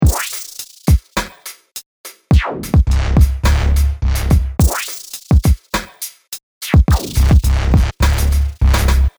嘻哈硬环
描述：嘻哈节奏的白色硬低音
Tag: 105 bpm Hip Hop Loops Drum Loops 1.54 MB wav Key : Unknown